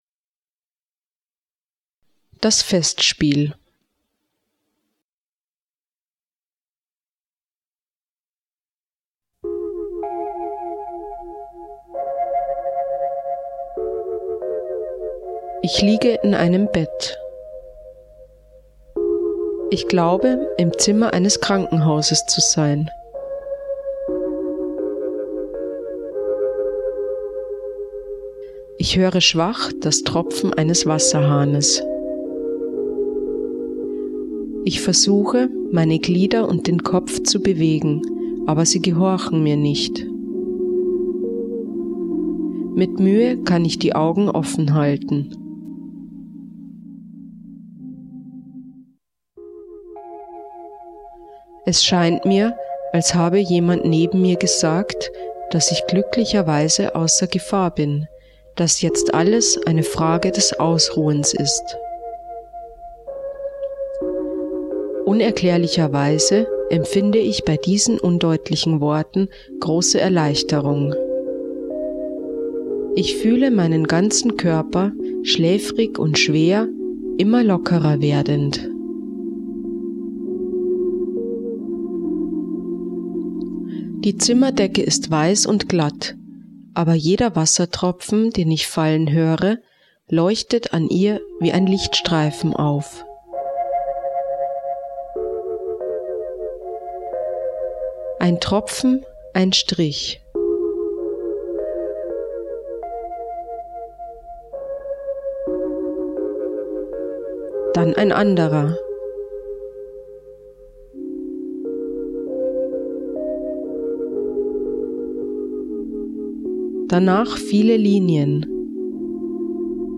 Geleitete Erfahrungen - Das Festspiel - Gemeinschaften von Silos Botschaft